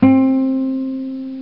Ac Guitar Hi Sound Effect
ac-guitar-hi.mp3